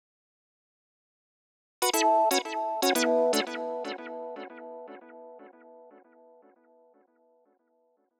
11 Solo Synth PT1.wav